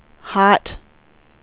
WindowsXP / enduser / speech / tts / prompts / voices / sw / pcm8k / weather_58.wav